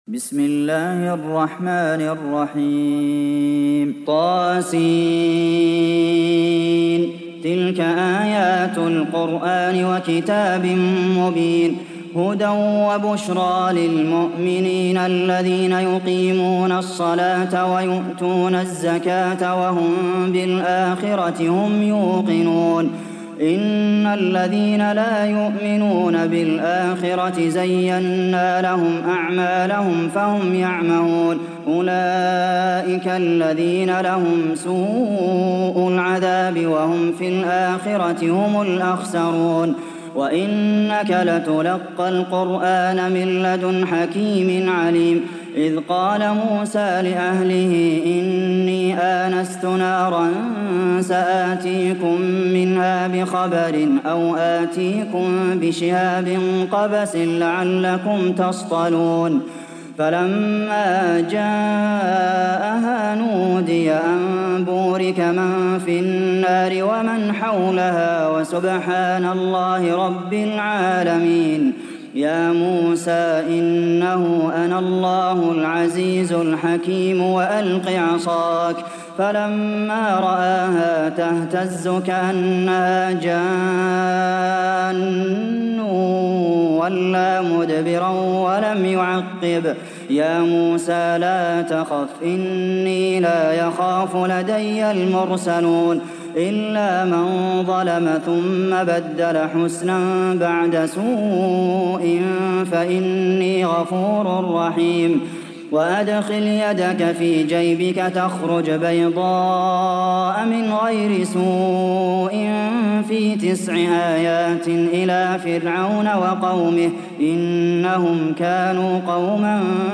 دانلود سوره النمل mp3 عبد المحسن القاسم (روایت حفص)